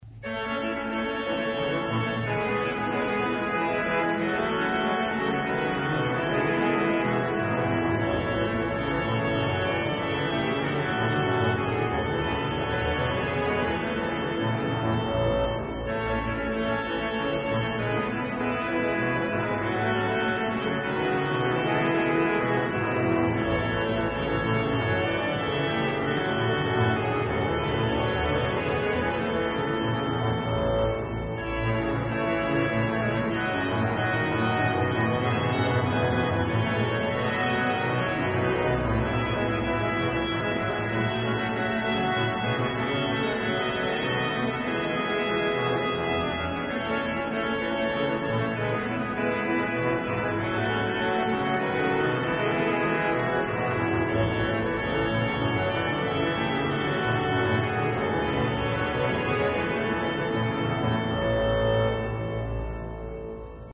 Concert d'inauguration du 3 juillet 1994